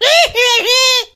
P先生只会像一只企鹅发出无意义的叫声，但是可以从中听出情绪。
Media:mrp_die_vo_01.ogg Mr. P cries
P先生的哀嚎